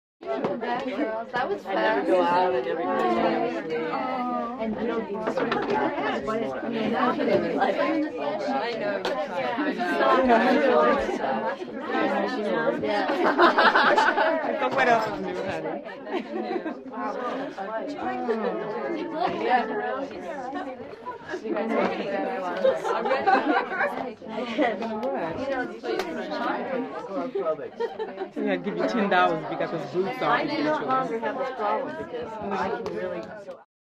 Звуки женских голосов
Шумная беседа женщин в веселой компании